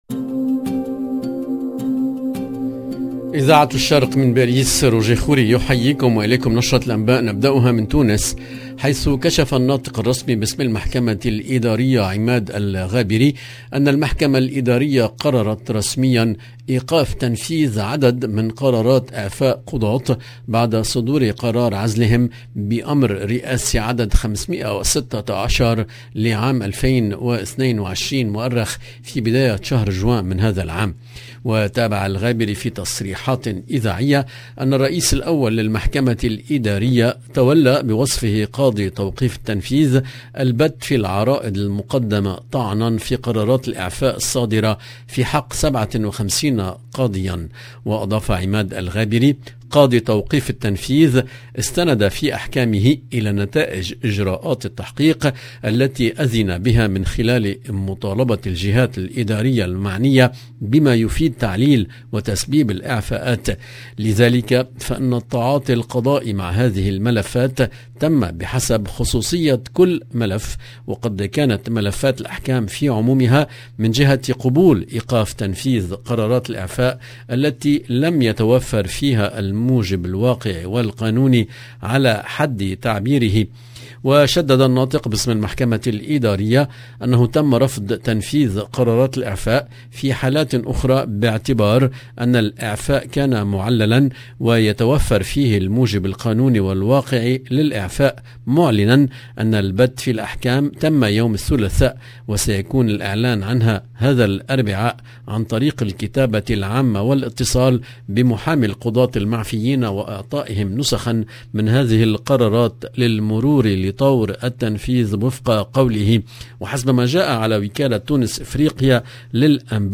LE JOURNAL DU SOIR EN LANGUE ARABE DU 10/08/22